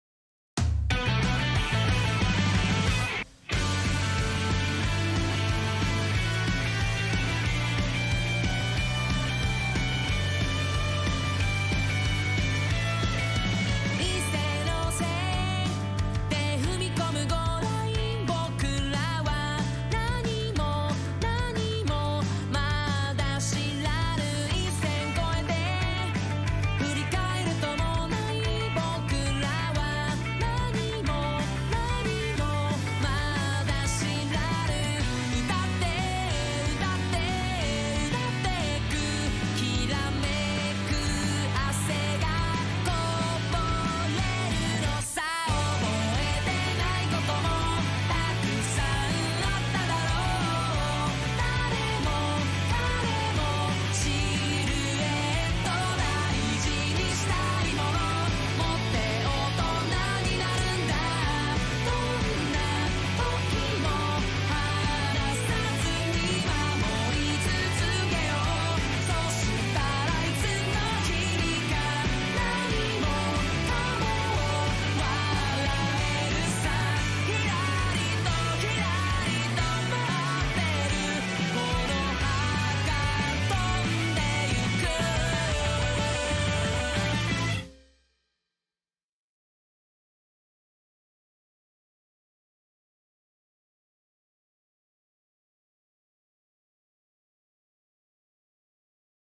A_goalhorn.mp3